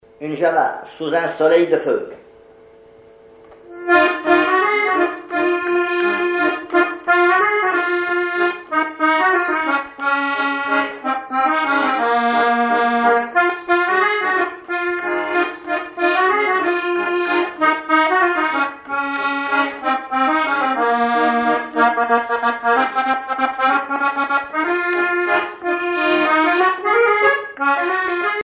accordéon(s), accordéoniste
danse : java
Pièce musicale inédite